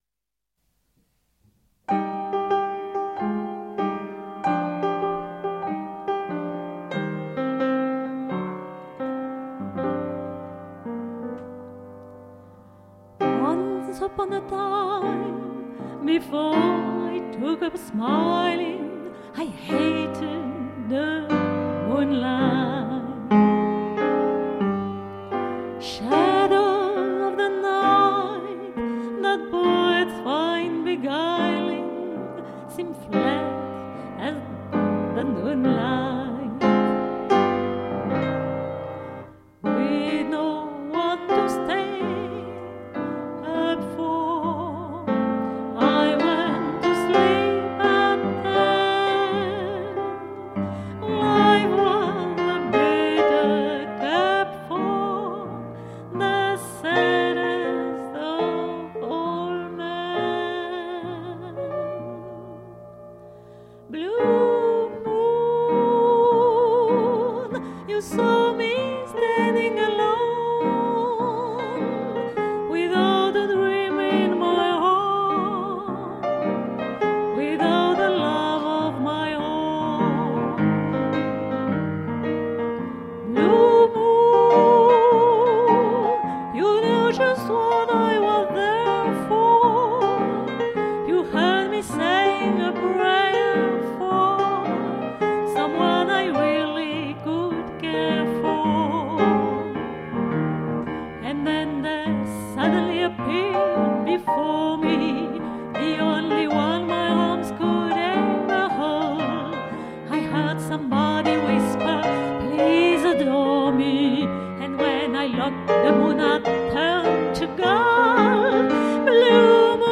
- Vieux thèmes de jazz
piano